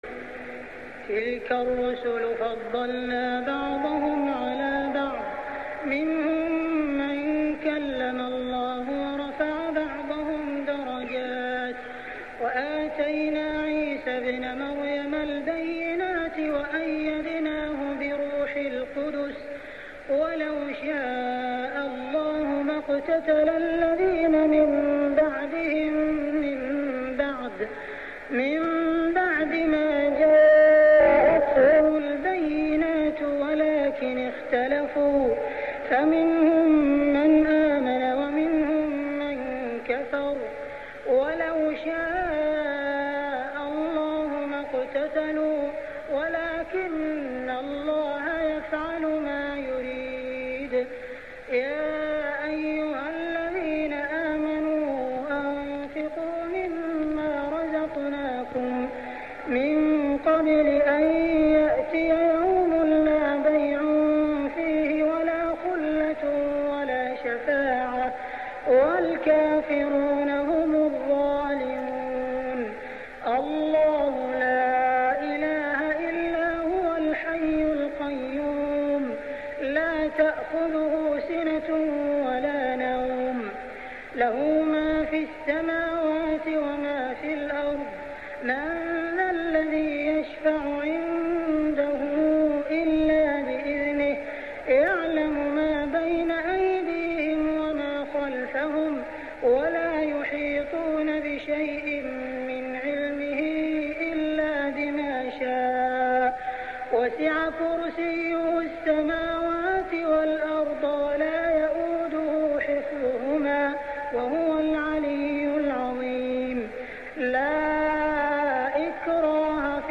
صلاة التراويح ليلة 4-9-1407هـ سورتي البقرة 253-286 و آل عمران 1-14 | Tarawih Prayer Surah Al-Baqarah and Al-Imran > تراويح الحرم المكي عام 1407 🕋 > التراويح - تلاوات الحرمين